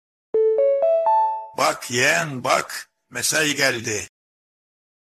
Ramiz Dayı, Uyan Yeğen, Sabah Oldu Alarm Sesi Zaza Dayı Mesaj Bildirim Sesi
Kategori: Zil Sesleri